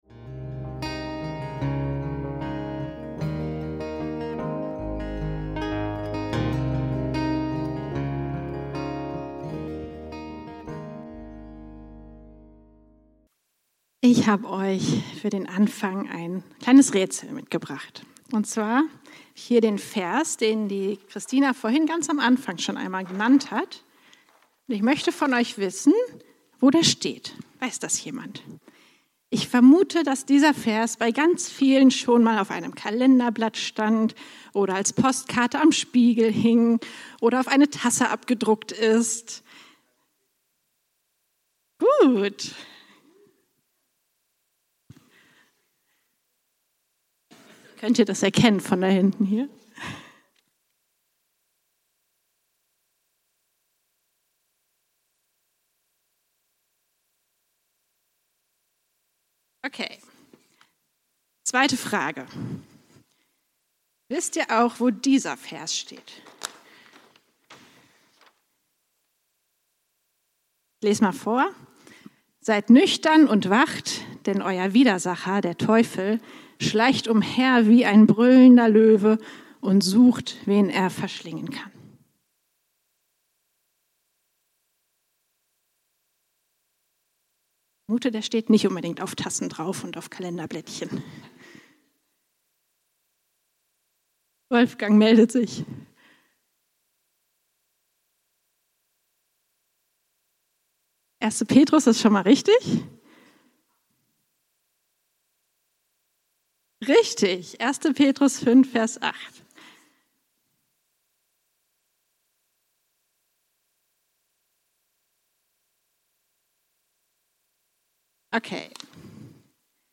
Alle Eure Sorge werft auf Ihn, denn er sorgt für Euch - Predigt vom 03.08.2025 ~ FeG Bochum Predigt Podcast